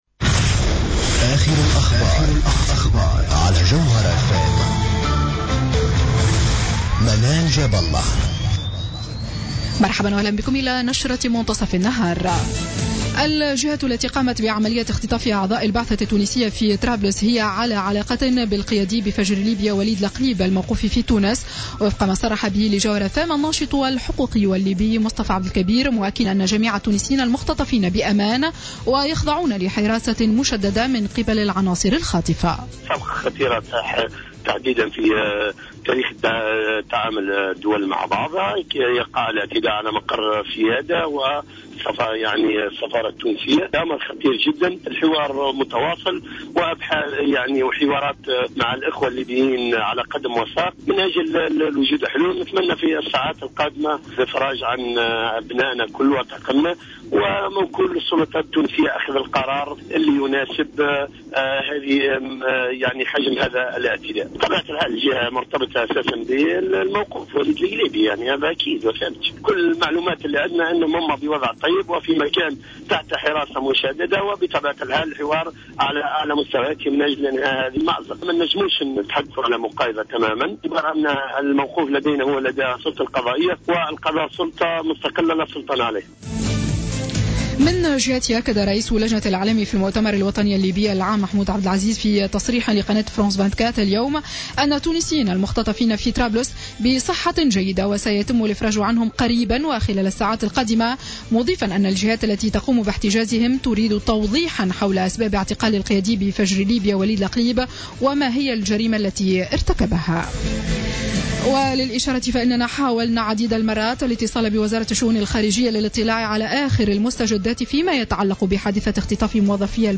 نشرة أخبار منتصف النهار ليوم السبت 13 جوان 2015